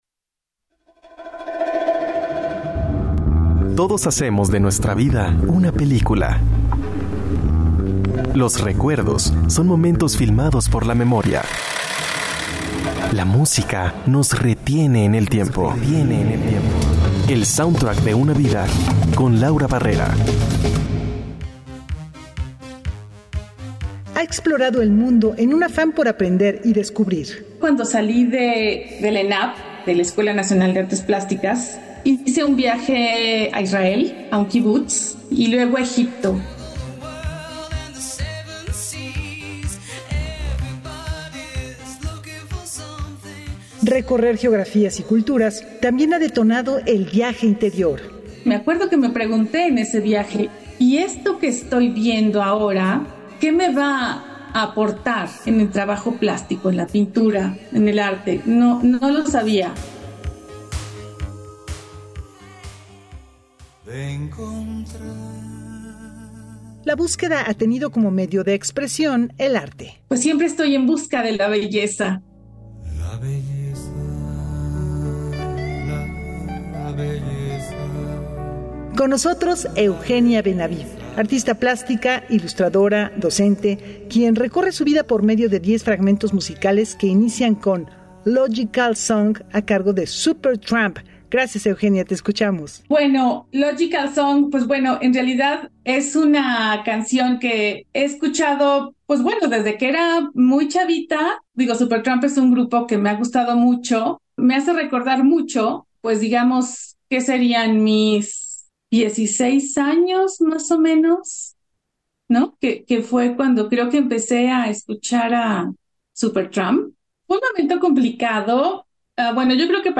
Sorprendentes acordes, emocionantes sonoridades electrónicas y un improbable vals son compañeros de vida.